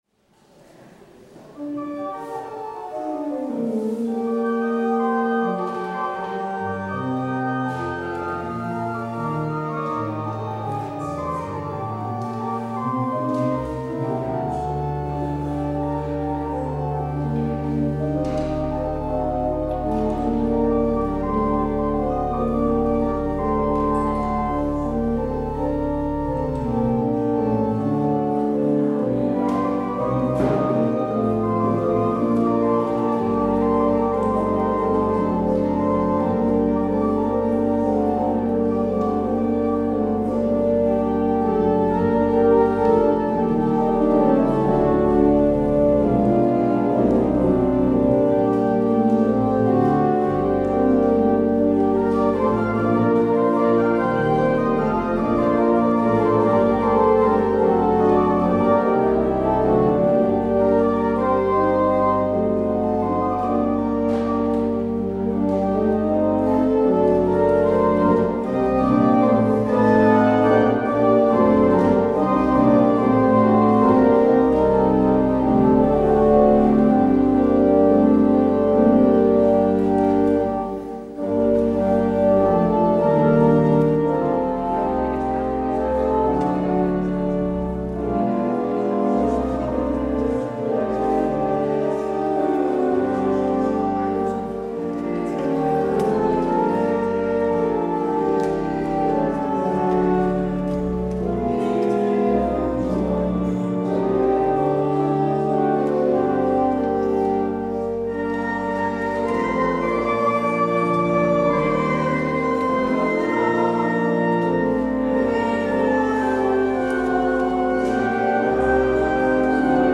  Luister deze kerkdienst hier terug: Alle-Dag-Kerk 31 januari 2023 Alle-Dag-Kerk https
Als openingslied hoort u Lied 695.